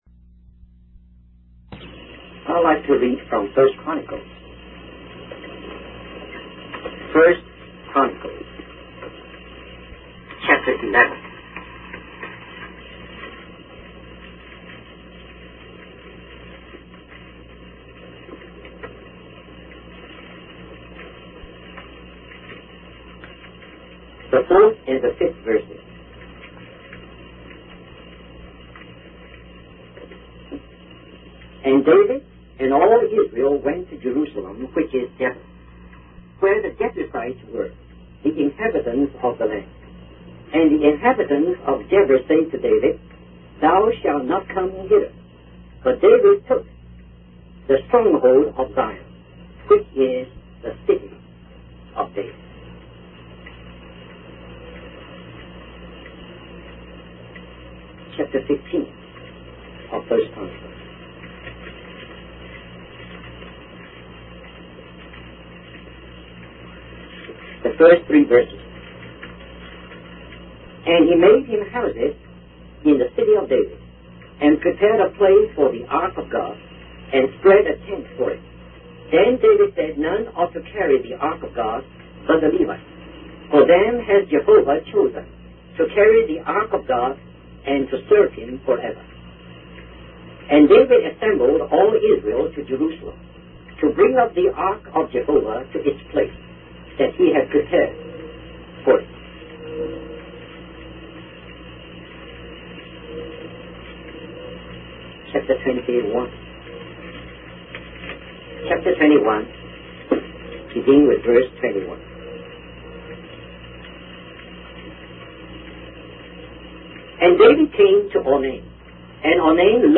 In this sermon, the speaker reflects on the importance of how our lives will be recorded by the Spirit of God.